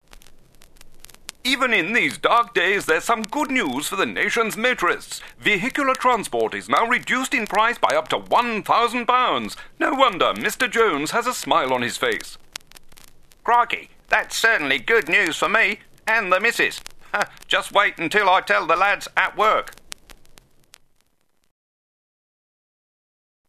Character and Cartoon voices
Old Newsreel style
old-newsreel-style.mp3